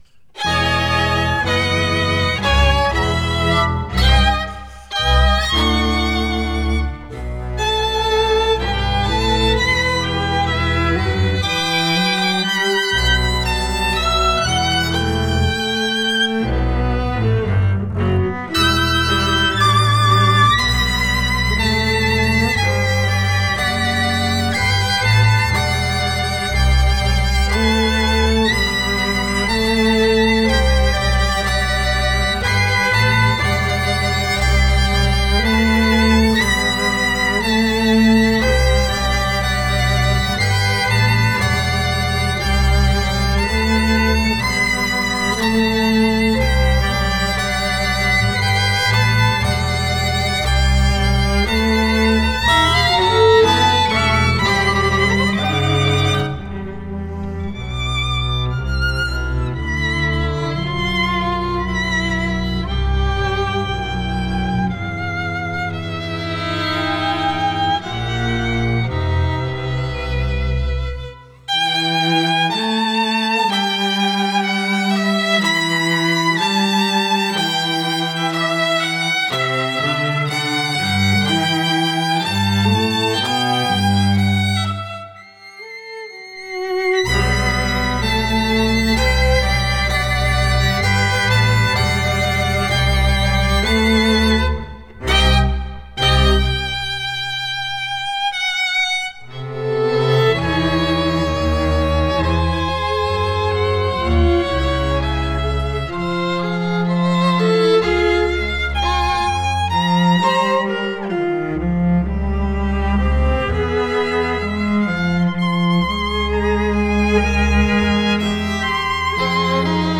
Arrangement for String Quintet recording.mp3 score.mus